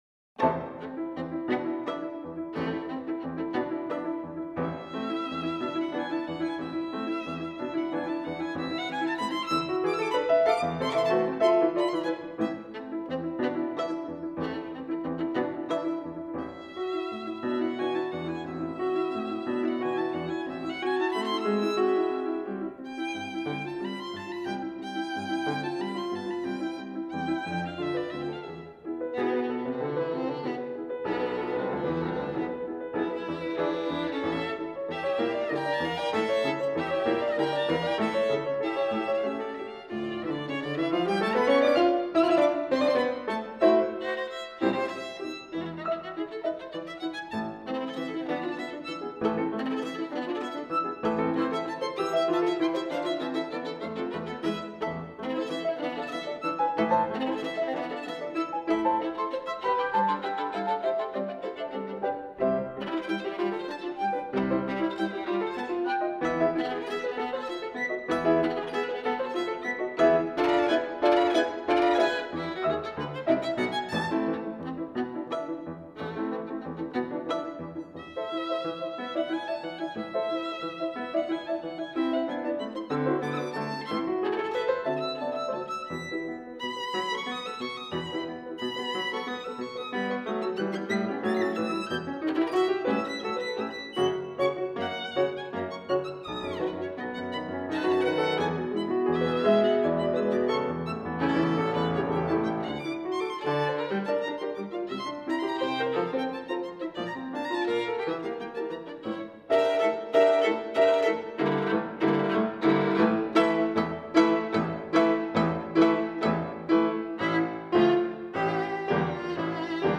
viool- piano